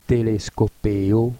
klik op het woord om de uitspraak te beluisteren